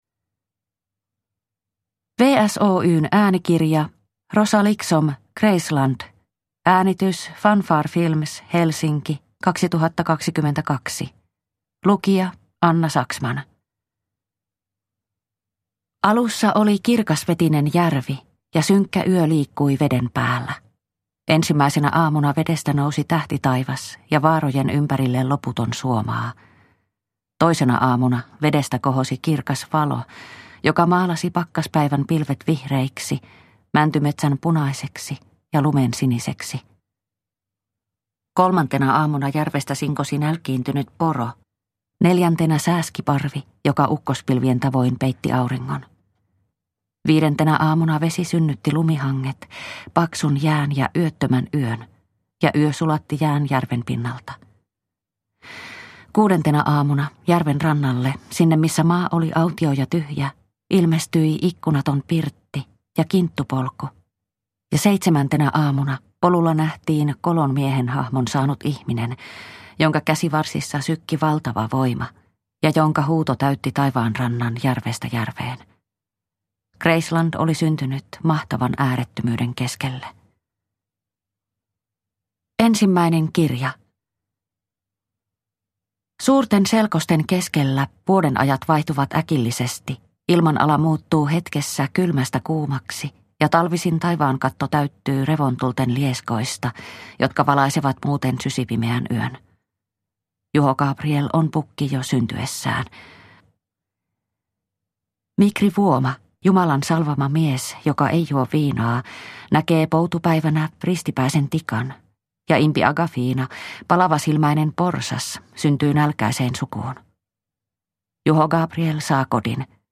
Kreisland – Ljudbok – Laddas ner